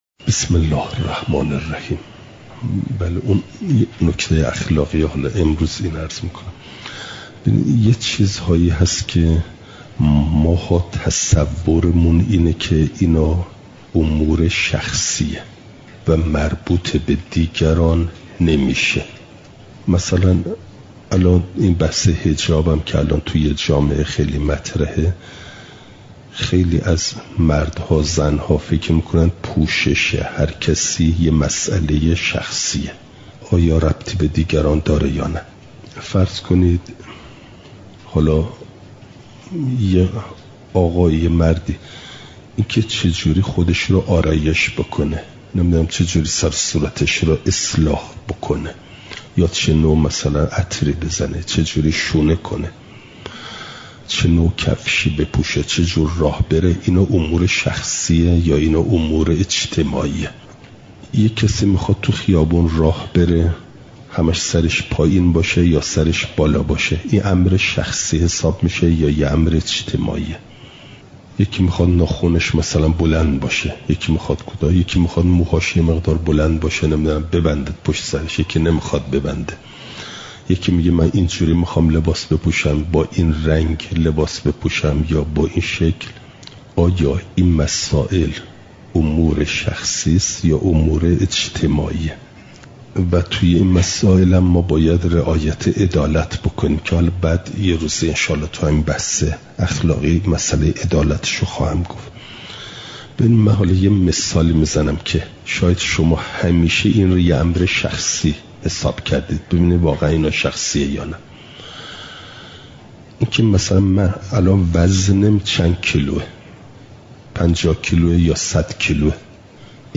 چهارشنبه ۵ مهرماه ۱۴۰۲، حرم مطهر حضرت معصومه سلام ﷲ علیها